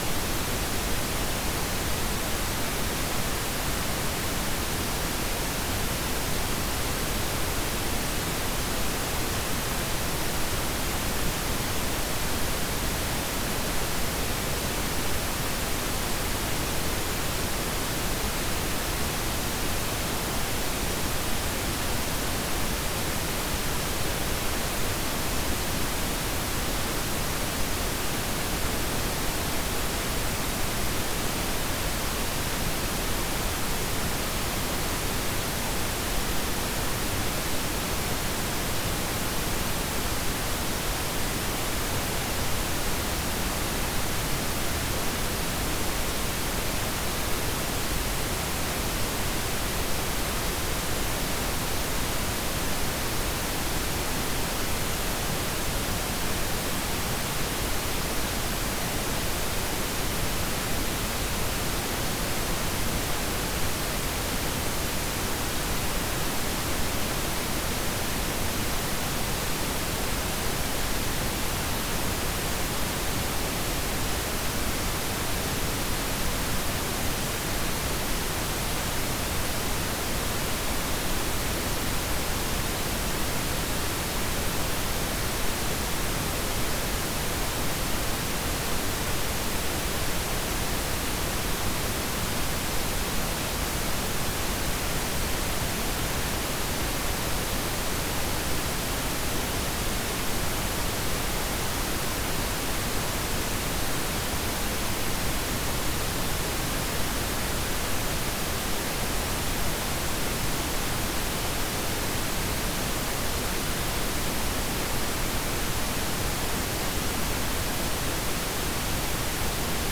10min_PinkNoise.mp3